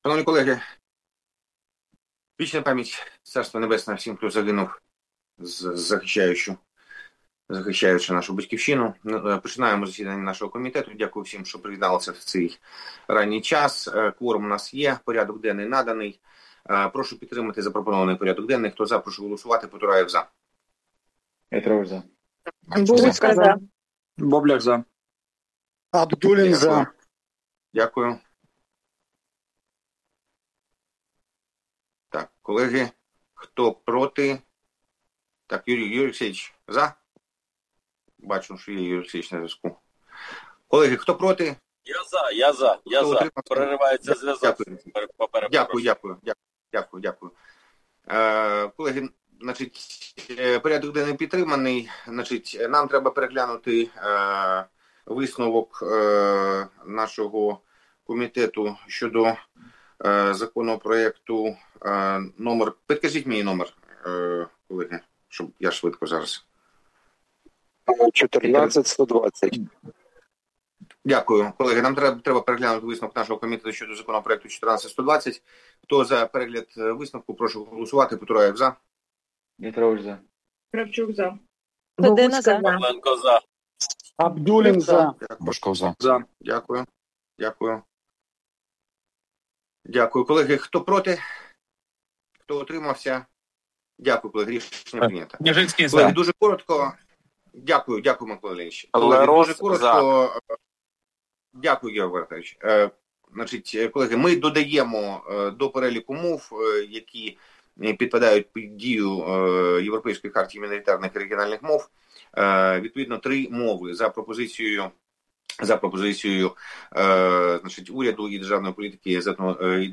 Назва файлу - Засідання Комітету 10 листопада 2025 року